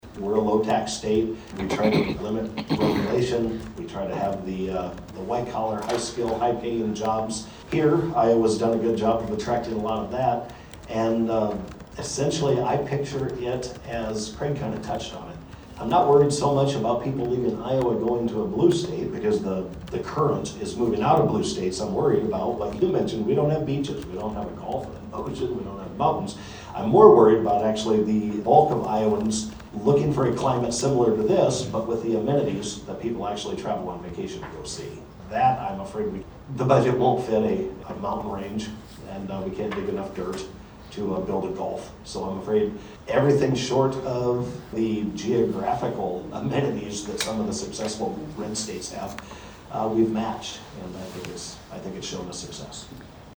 The Carroll Chamber of Commerce and Carroll County Growth Partnership (CCGP) hosted its third legislative forum of the 2026 session on Saturday, and the future of Iowa’s workforce was one of the questions brought to District 6 Sen. Jason Schultz (R-Schleswig) and District 11 Rep. Craig Williams (R-Manning).